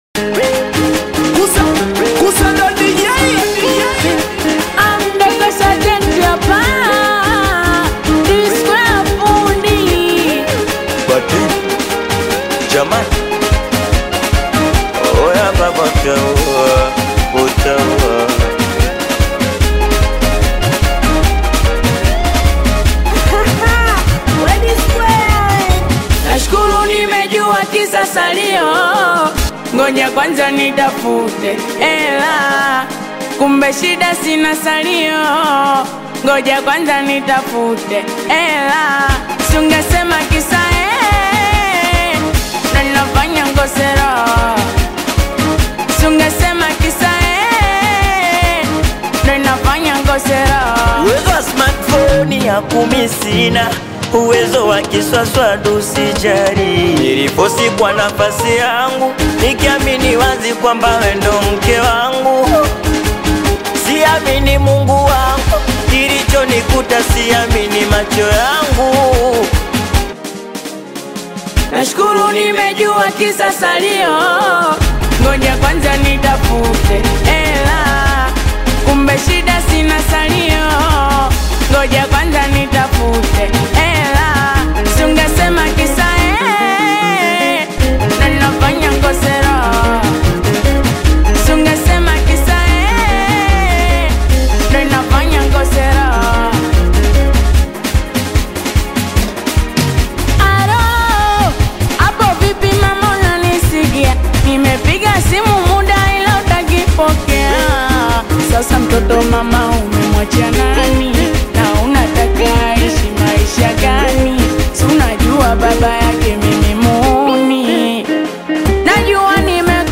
Singeli Energetic music